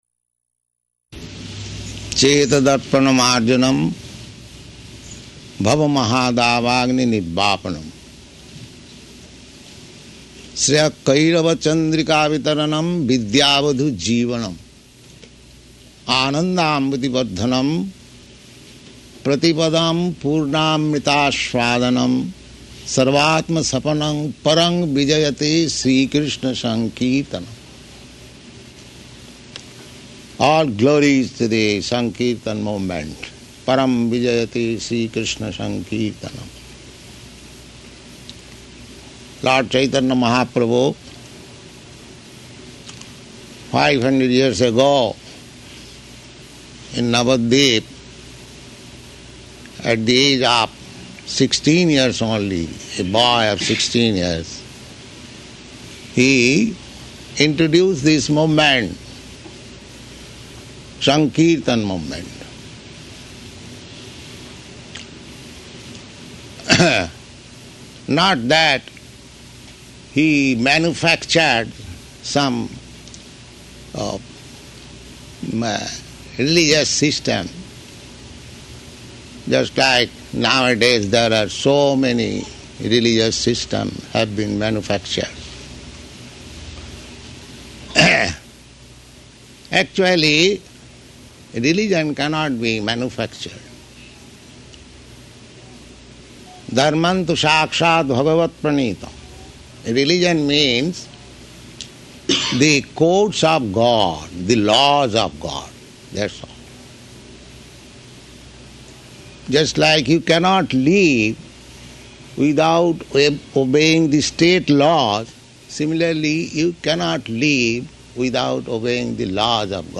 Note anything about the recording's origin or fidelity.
Location: Colombus